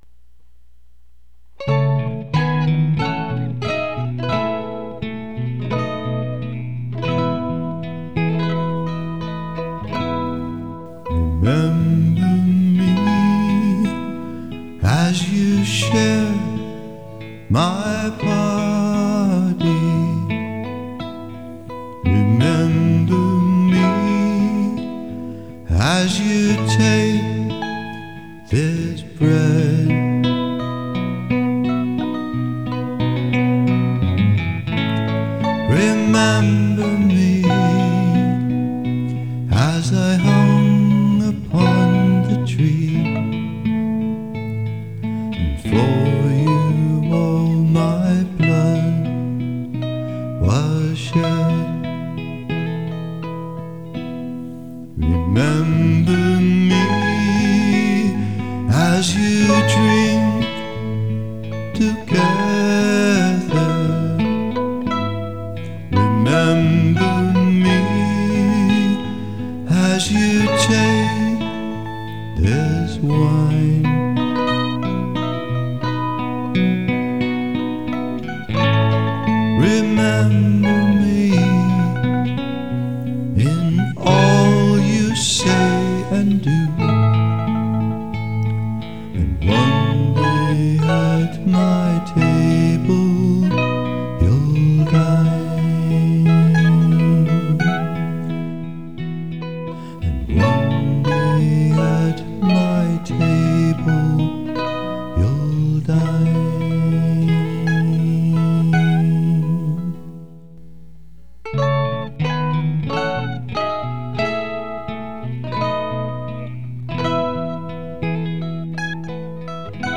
Worship Songs: